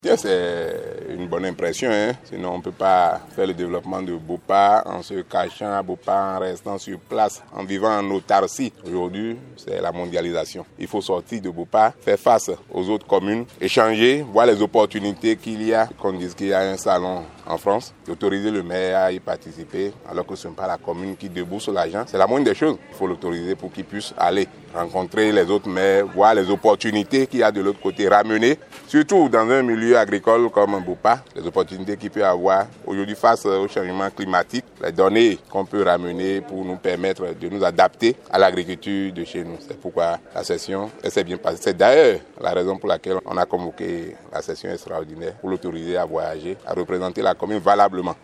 Au terme des travaux de cette session, le chef d’arrondissement de Lobogo livre ses impressions et évoque les raisons qui ont motivé le conseil communal à autoriser le voyage du maire sur Paris.